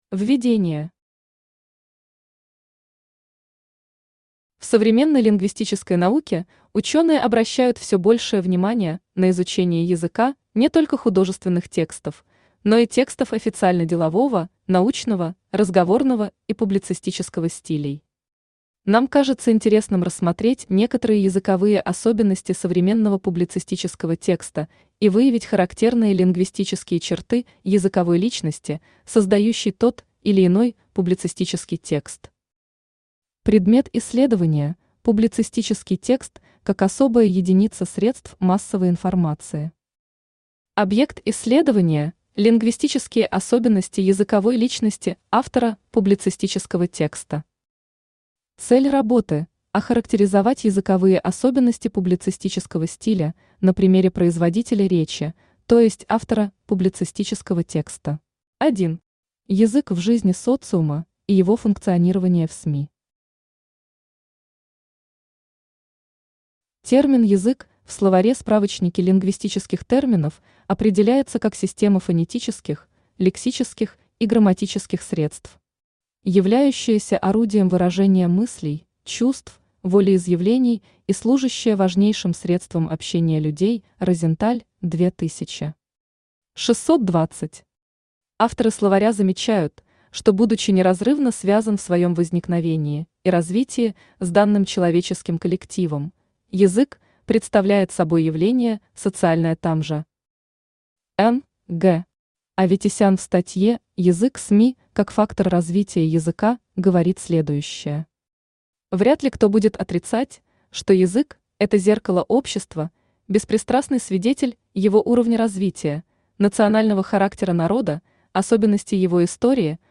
Аудиокнига Проблема выявления языковой личности автора текста